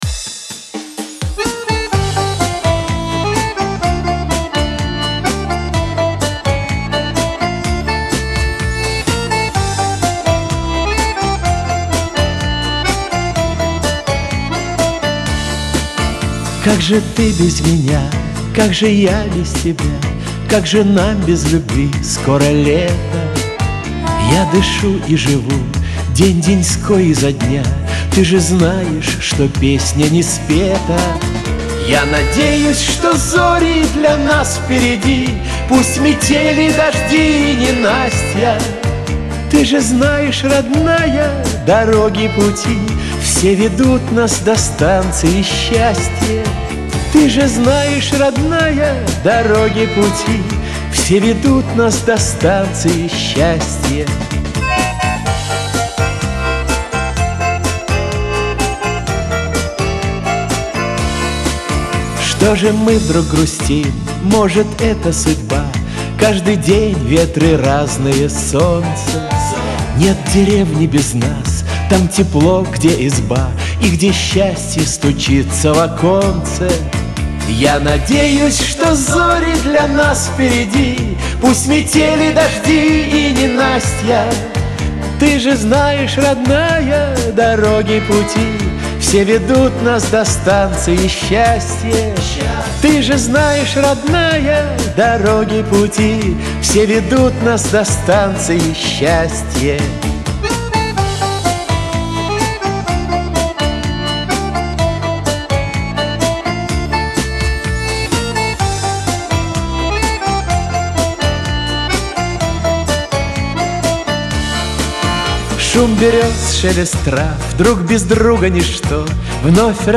pop
Шансон